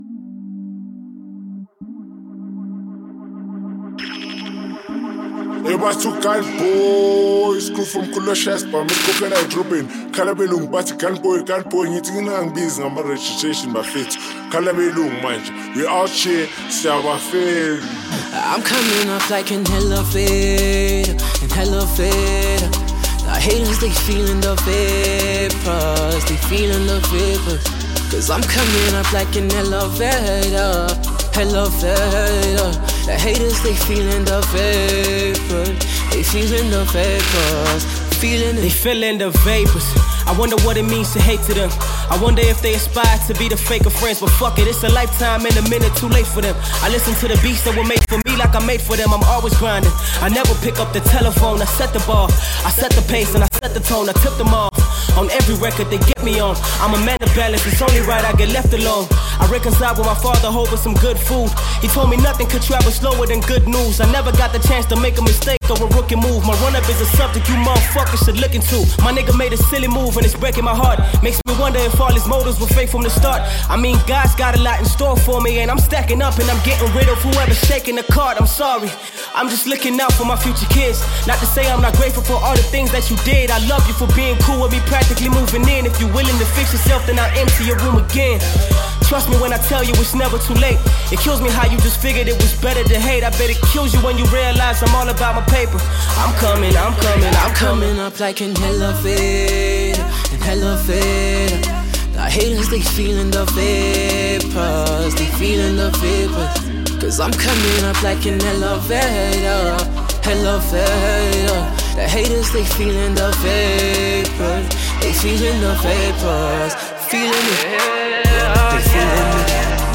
S African Hip Hop heavyweight